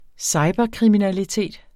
Udtale [ ˈsɑjbʌ- ]